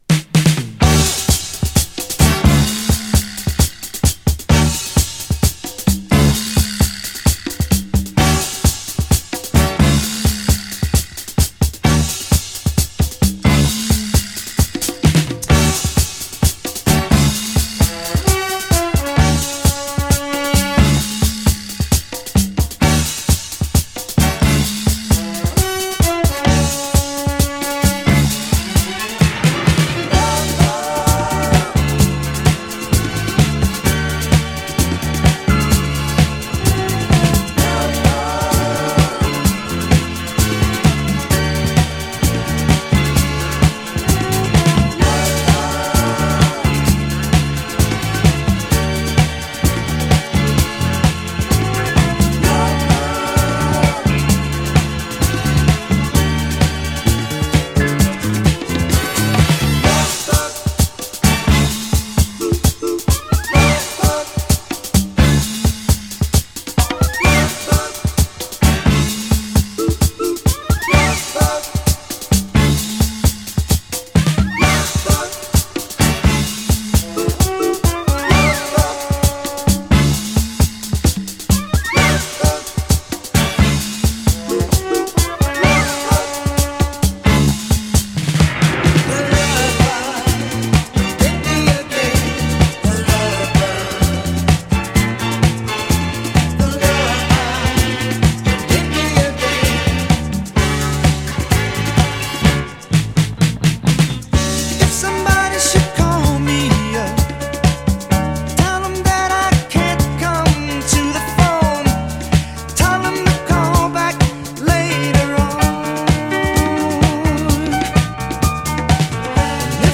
SOUL
BLUE EYED SOUL〜MODERN BOOGIE !! 50…